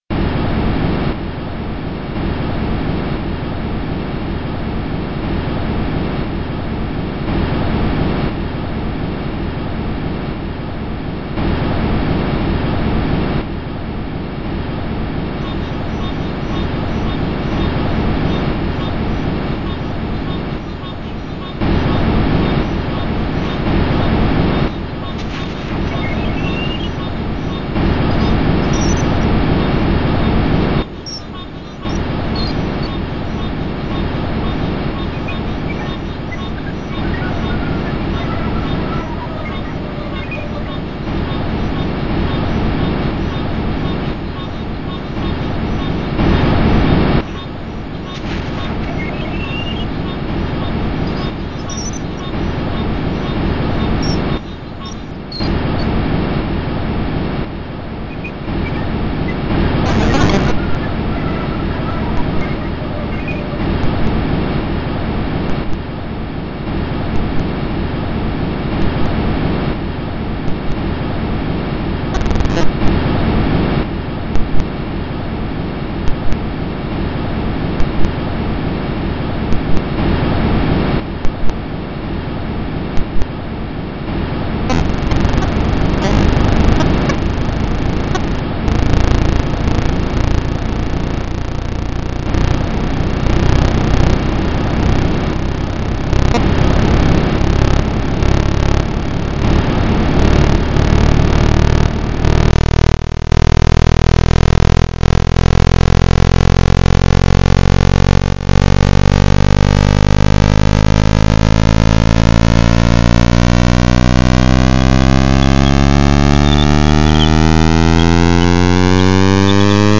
piano piece
midi version